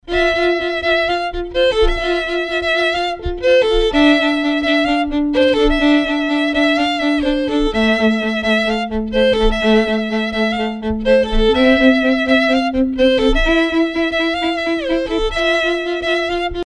Звуки скрипки